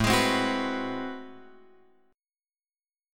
A Minor Major 13th